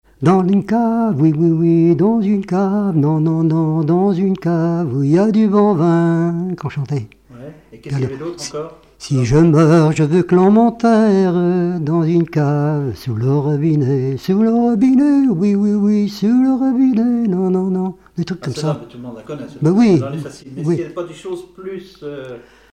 circonstance : bachique
Genre strophique
regroupement de chanteurs locaux
Pièce musicale inédite